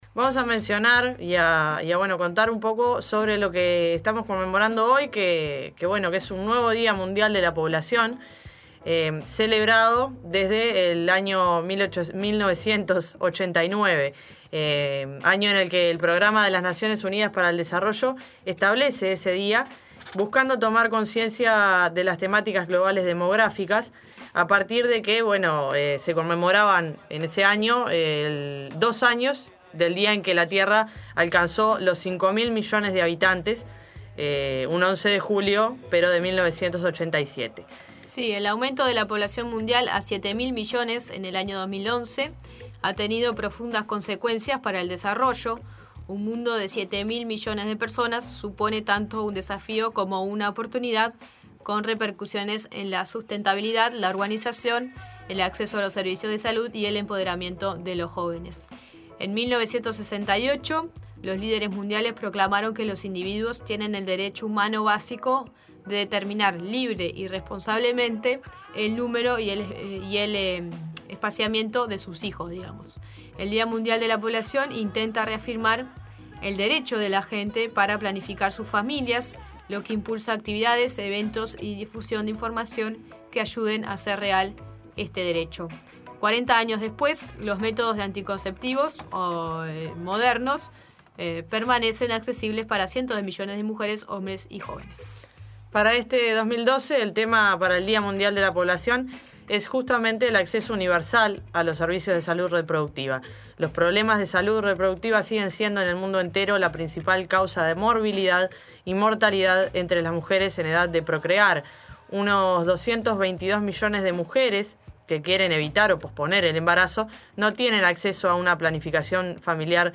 Podcast: Informe sobre el Dia Mundial de la Poblacion
La Nueva Mañana realizó un informe sobre nuestra población y los avances en materia de Salud Sexual y Reproductiva.